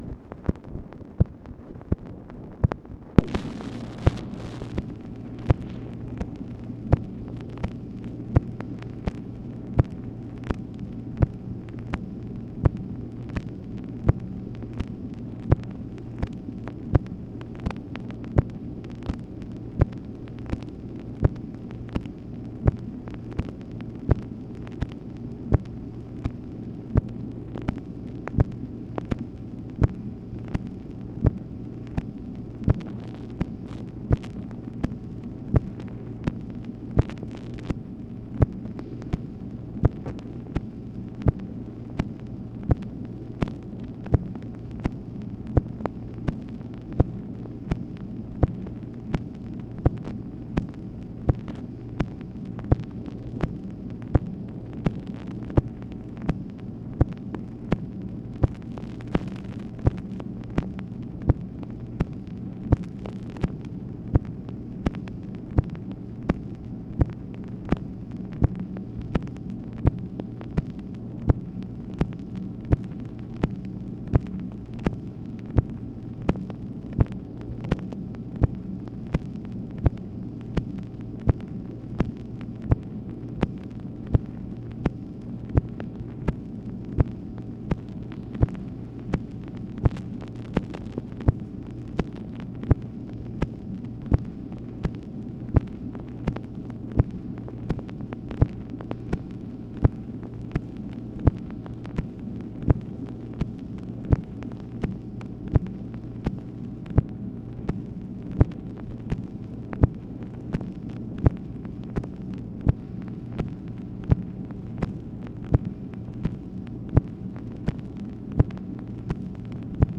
MACHINE NOISE, June 9, 1965
Secret White House Tapes | Lyndon B. Johnson Presidency